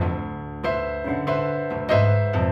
Index of /musicradar/gangster-sting-samples/95bpm Loops
GS_Piano_95-D1.wav